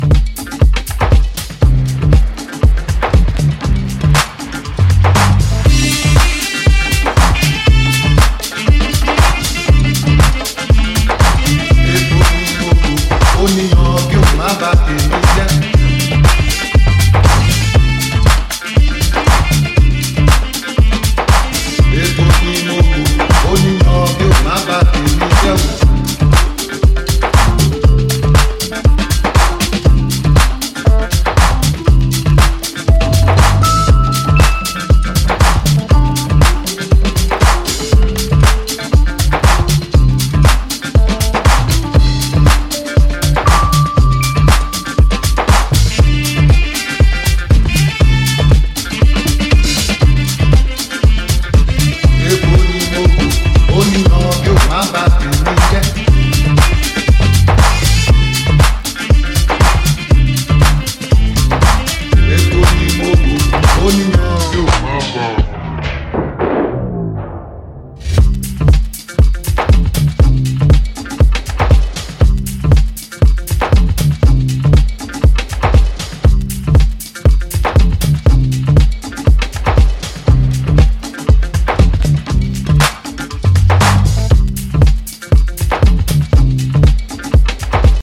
House
bringin some summer jungle jams